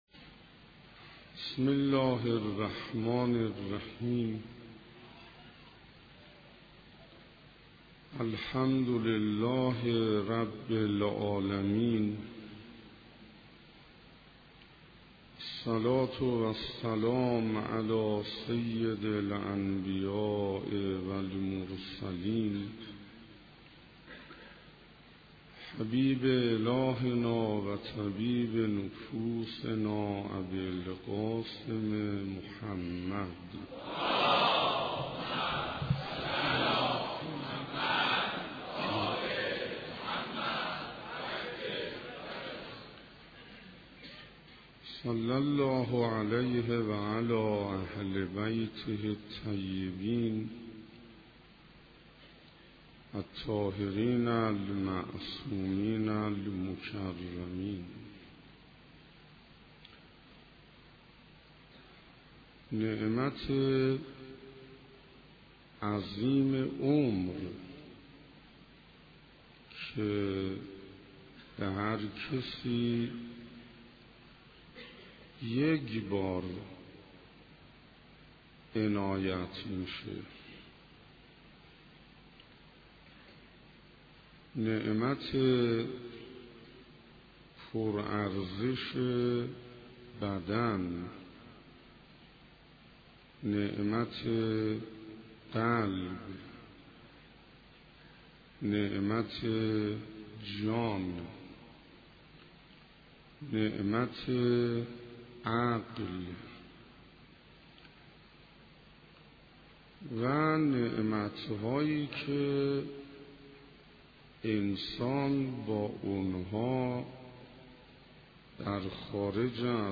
صوت کامل سخنرانی شیخ حسین انصاریان
عقیق: اولین شب از برگزاری مراسم لیالی قدر در حسینیه همدانی ها برگزار شد.